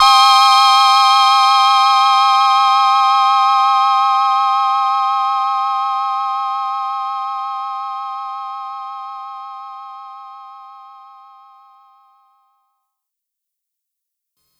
Thin Synth.wav